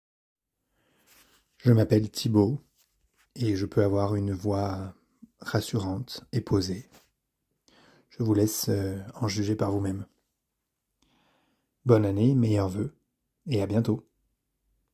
Bandes-son
Voix OFF rassurante et posée